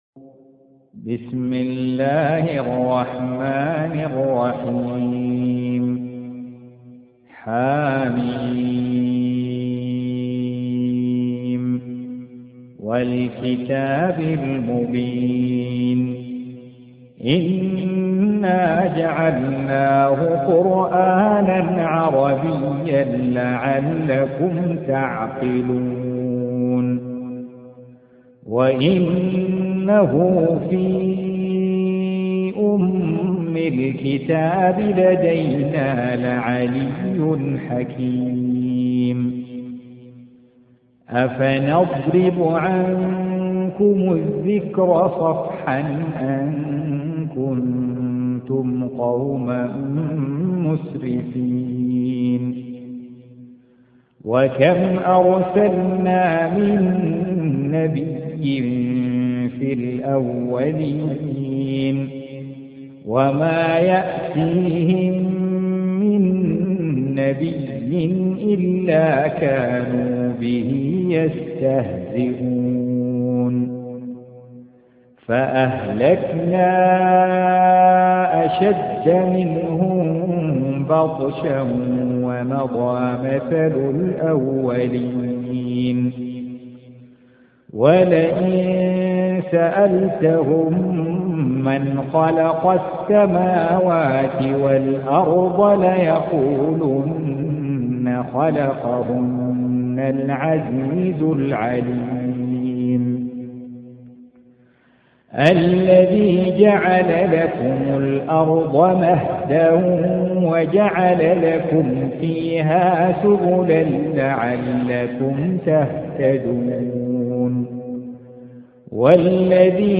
Surah Repeating تكرار السورة Download Surah حمّل السورة Reciting Murattalah Audio for 43. Surah Az-Zukhruf سورة الزخرف N.B *Surah Includes Al-Basmalah Reciters Sequents تتابع التلاوات Reciters Repeats تكرار التلاوات